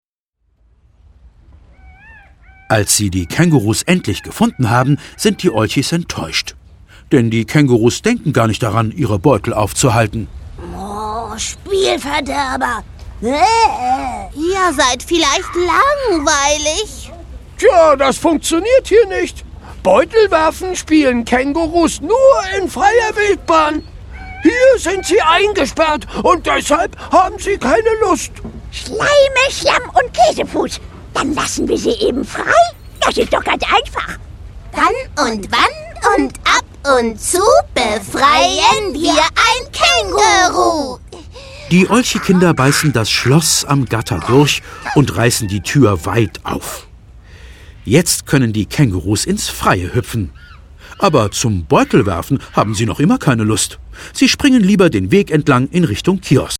Szenische Lesung
Szenische Lesung mit den frechen Olchi-Stimmen.